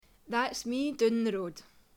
Examples of Scottish English
//ðæts mi dʉn ðə roud//
Notice the /ʉ/ pronunciation of down.
01_Scots.mp3